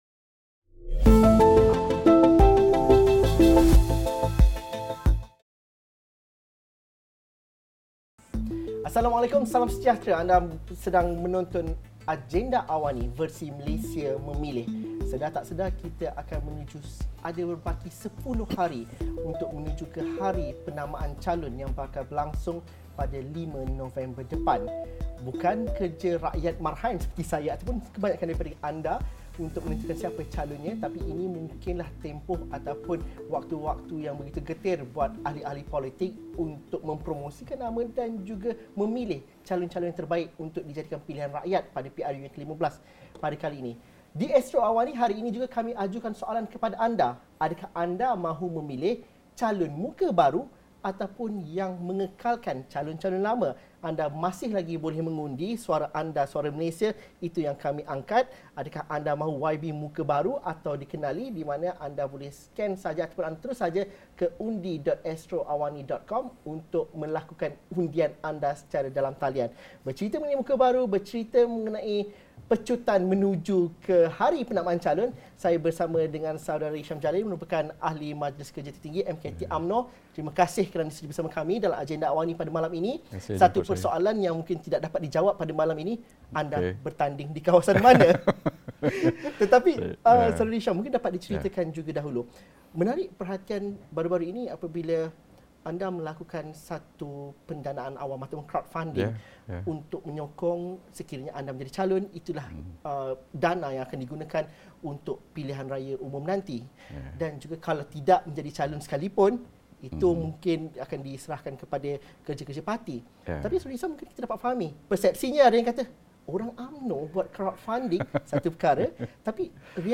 Bersediakah parti-parti politik untuk meletakkan calon muka baharu lebih ramai berbanding yang berpengalaman pada PRU15? Diskusi 8.30 malam